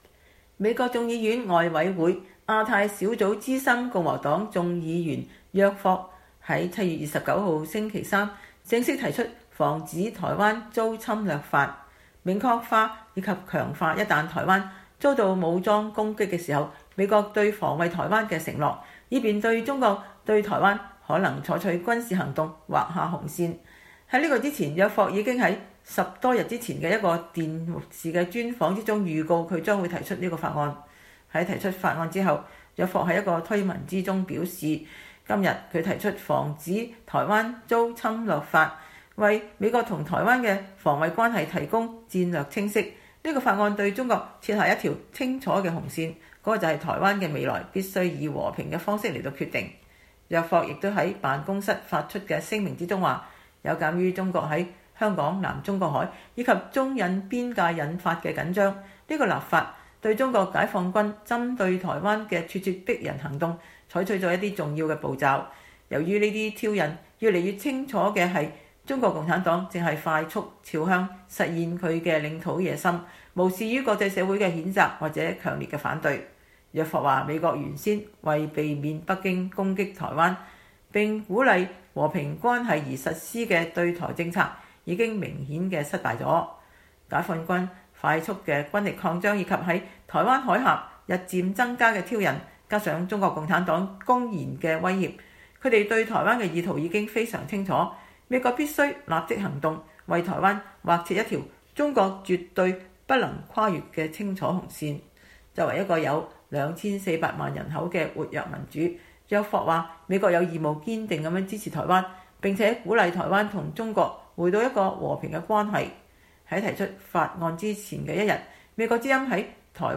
在提出法案前一天，美國之音在台灣駐美代表處捐贈口罩的儀式中問約霍，他如何看待蓬佩奧上星期被認為是正式宣告美中冷戰開始的演說，以及這是否為美國對台政策帶來一些機會，約霍在答覆中說，這就是他提《防止台灣遭侵略法》的目的。